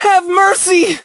hotshot_die_03.ogg